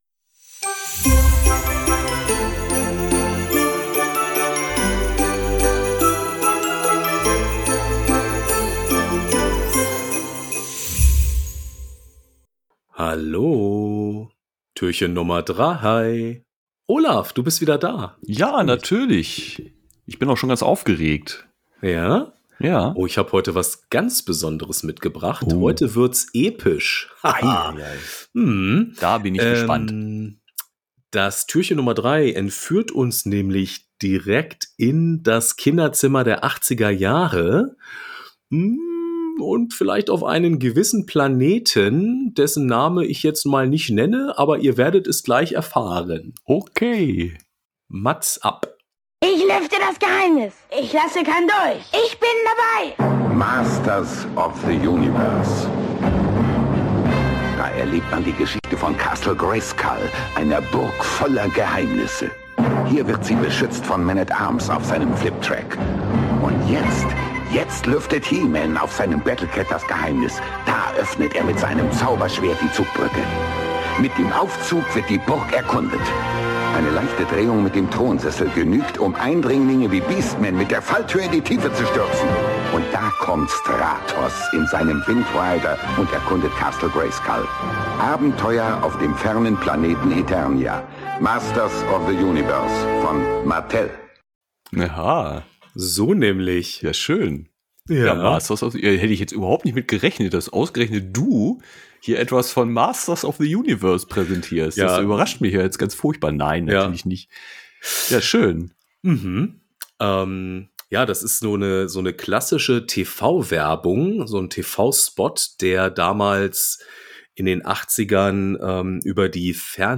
Es ist wieder Zeit für ein akustisches Rätsel aus der